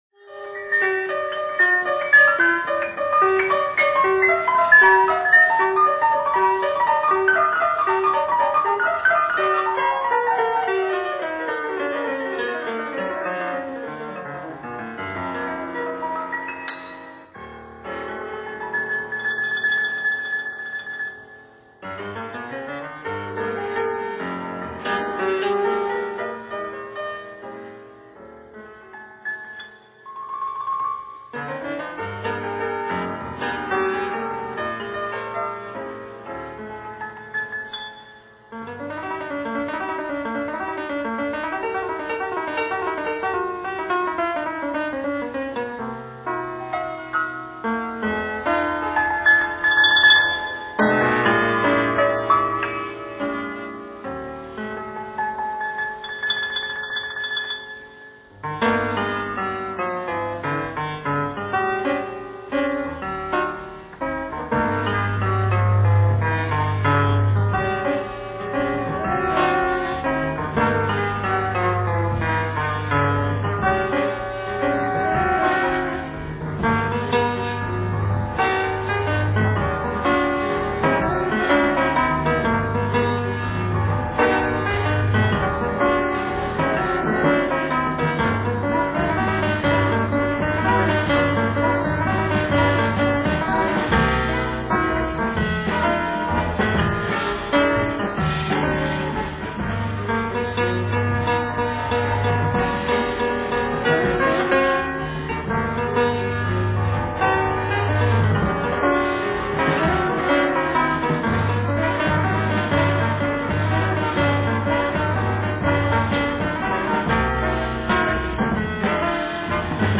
Piano
Tenor Sax
Flugelhorn
Baritone Sax
Conga Drums
Bass